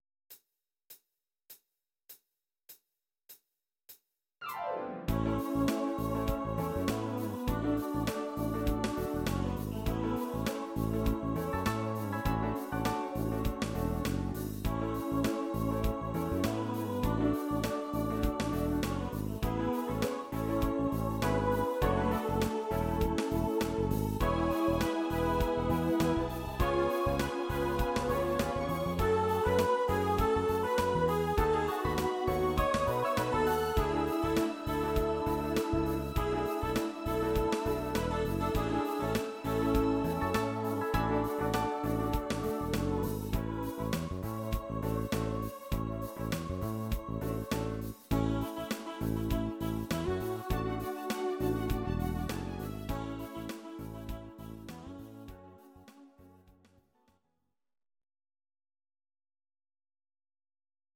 Audio Recordings based on Midi-files
Our Suggestions, Pop, Medleys